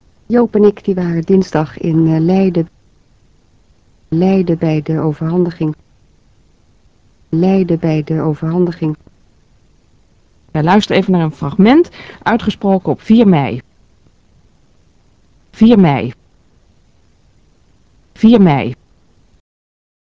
Sound sample nr. 1 concerns some fragments of ABN-Dutch;
Figure 1. Fragments casual spoken Dutch:ABN
they are taken from daily radio-programs.
Essential fragments are repeated twice or three times, as the text shows.
Both of them are speaking ABN-Dutch as can be heard from their ij sounds.